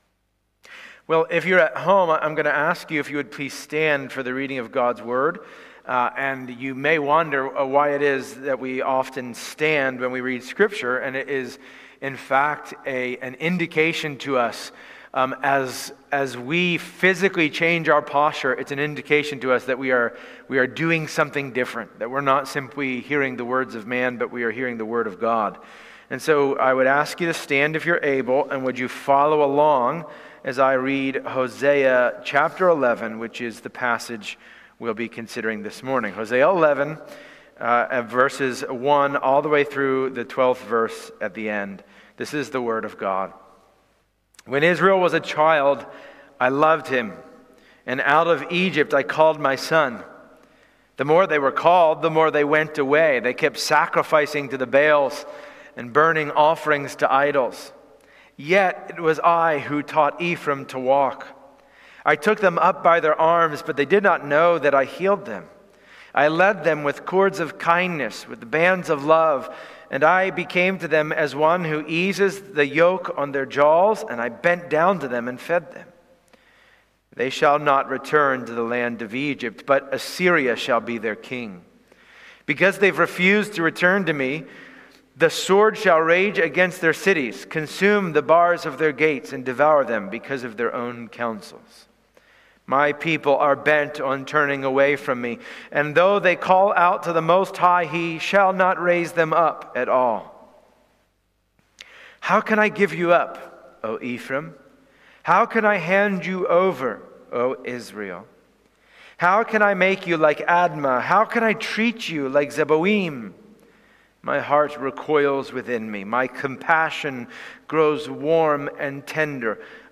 Passage: Hosea 11 Service Type: Sunday Morning Download Files Bulletin « Plowing